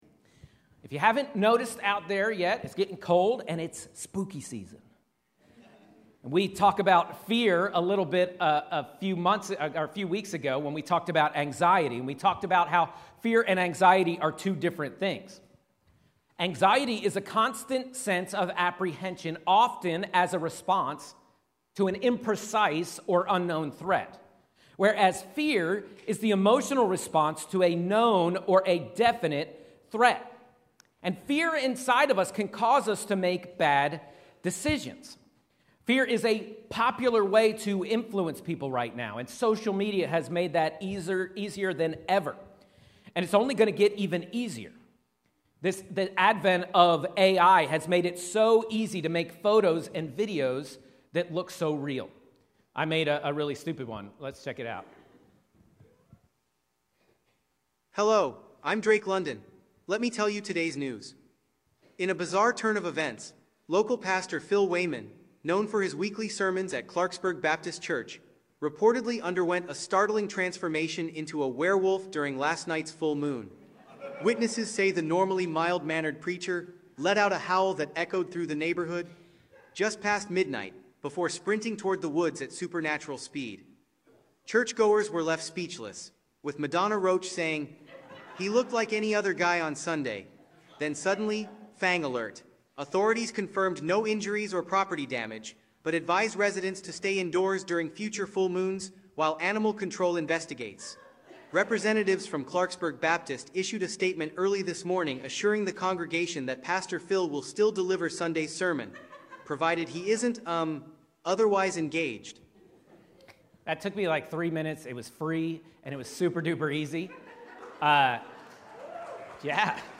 A message from the series "Inside Out." You can’t heal what you refuse to reveal.